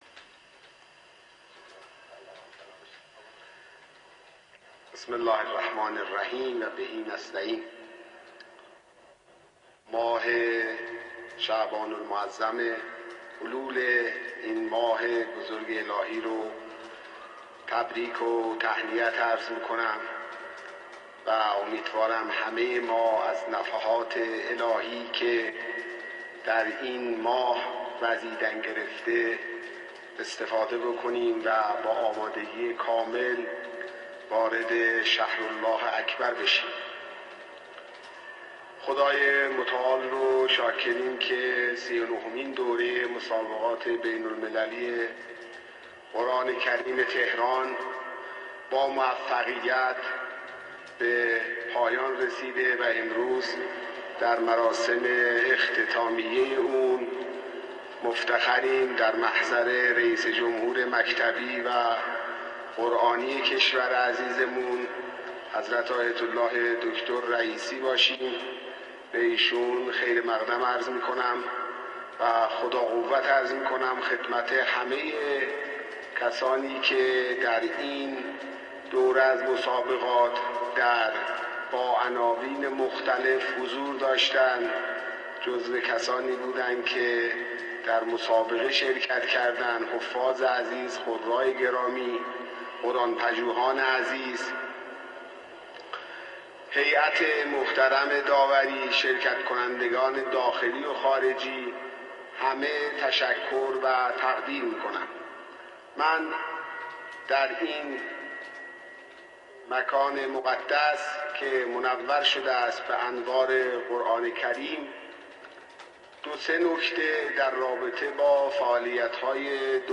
حجت‌الاسلام والمسلمین ابراهیم رئیسی، رئیس‌جمهور کشورمان در این بخش از مراسم به سخنرانی پرداخت.
یادآور می‌شود، این مراسم به صورت زنده از شبکه قرآن و معارف سیما پخش شد اما صوت پخش زنده کیفیت مناسبی نداشت و بارها میزان صدا افزایش و کاهش یافته و یا ثانیه‌هایی قطع و ناواضح می‌شد.